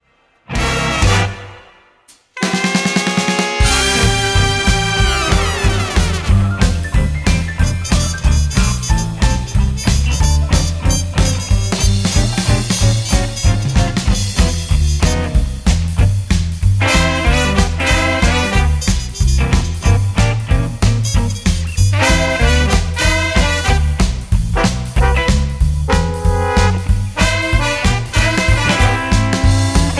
Gm
Backing track Karaoke
Pop, 1990s